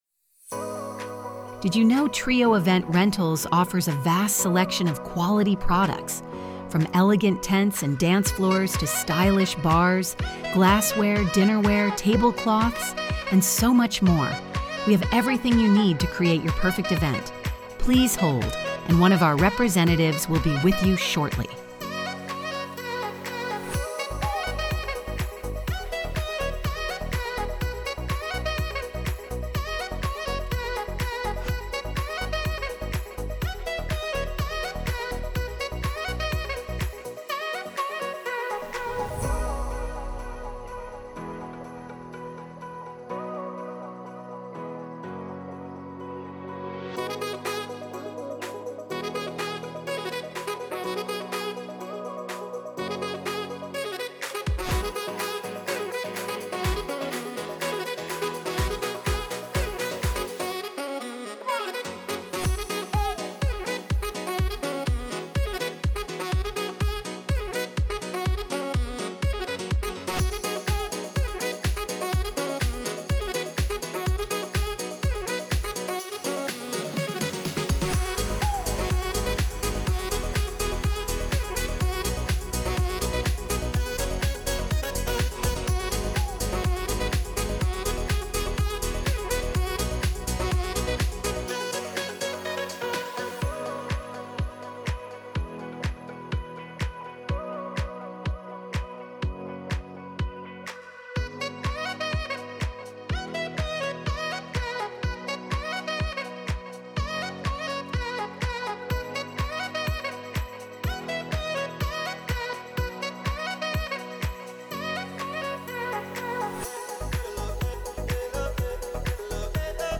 hold_music.mp3